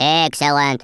Worms speechbanks
excellent.wav